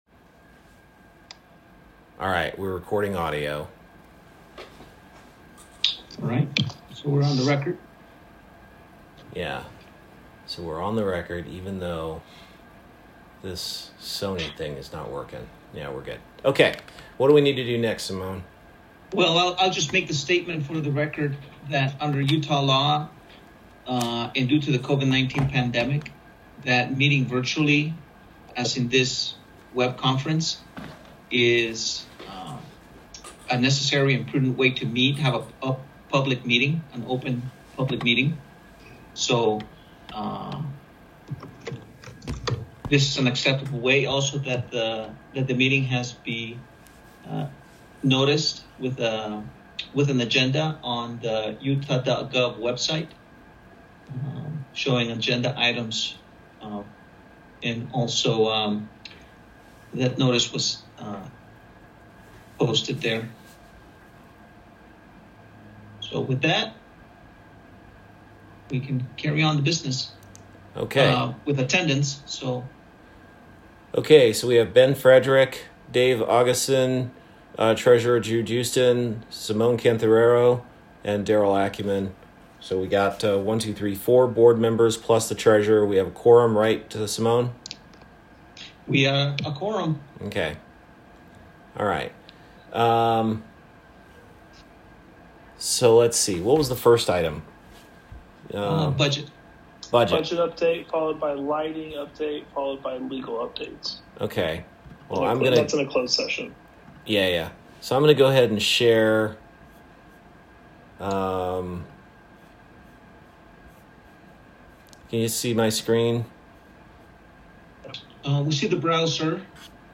Meeting recording